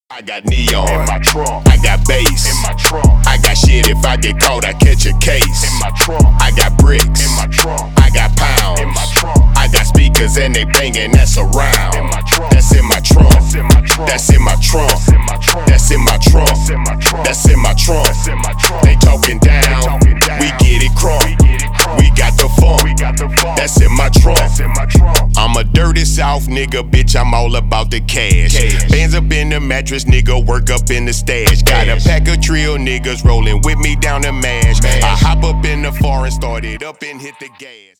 • Качество: 320 kbps, Stereo
Рэп и Хип Хоп